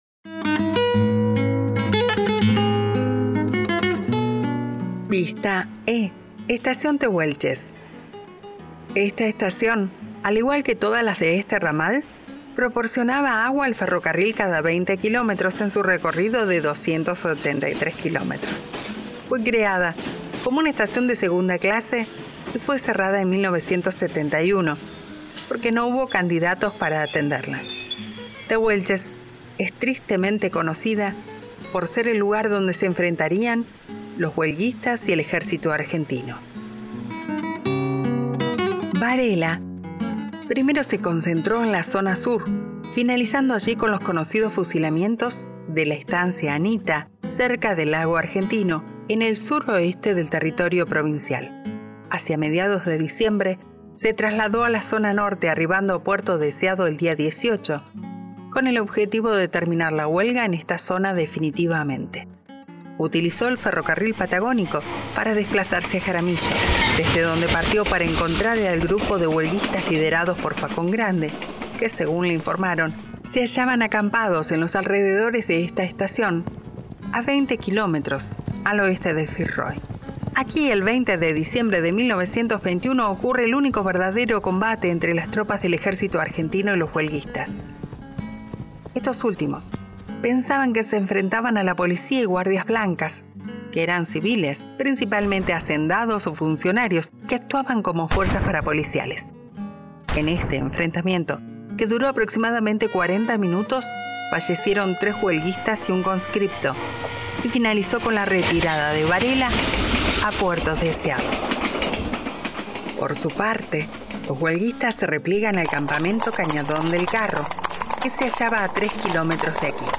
Audioguía Vehicular Huelgas Patagónicas